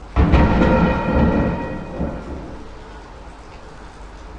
描述：正在努力上传我的索尼M10的录音，抱歉一直在重复。来自附近的火车厂，重金属物体被扔进金属容器/跳板/垃圾箱的声音。
Tag: 崩溃 工厂 金属 噪声 工业